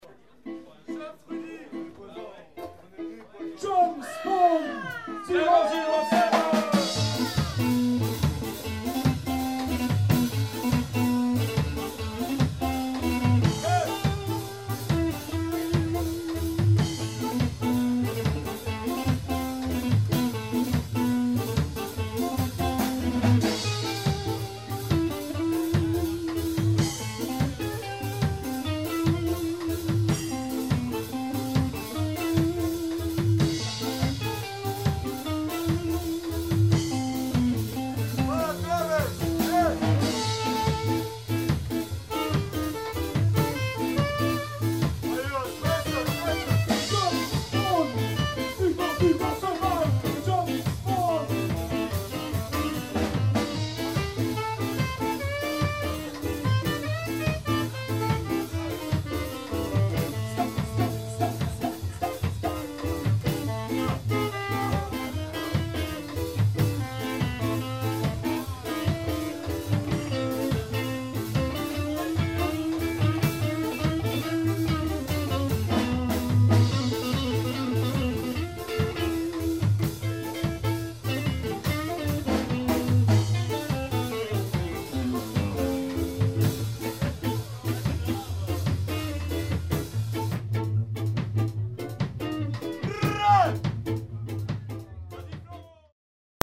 Reggae Roots from Strasbourg (voir interview).